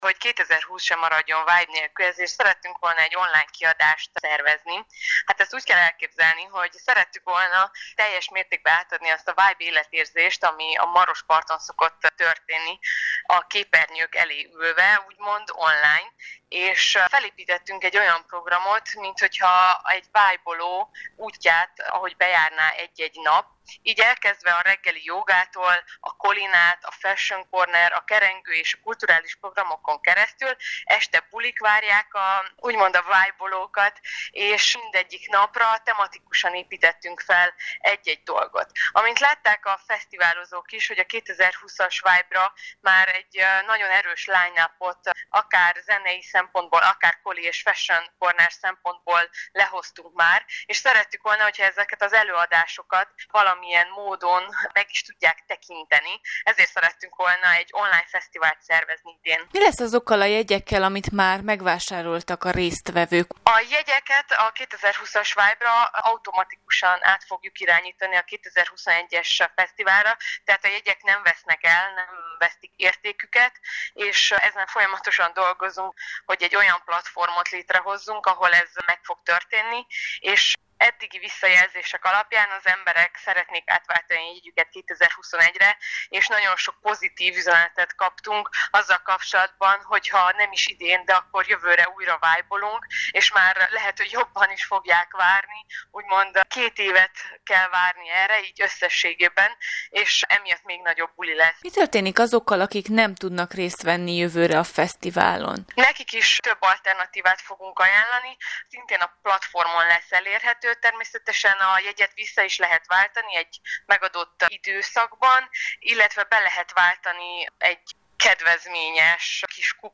VIBE-FESZTIVAL-FJ.mp3